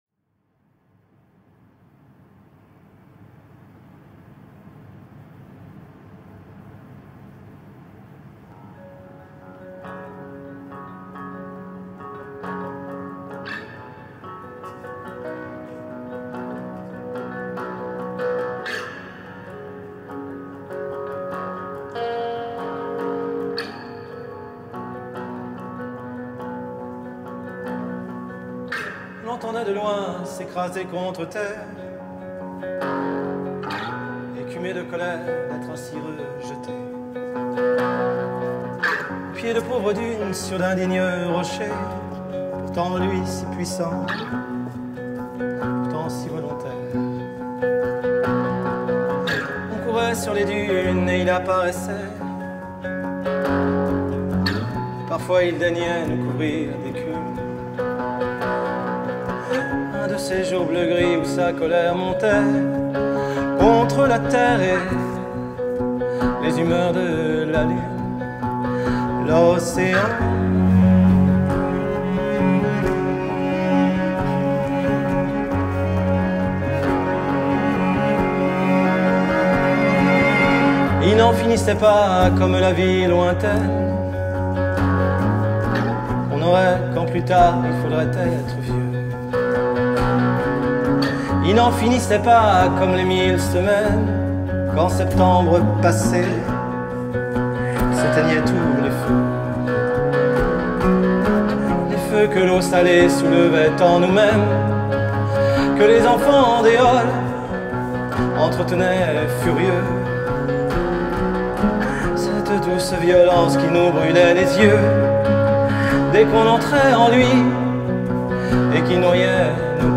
Most stunning of dreamscapes.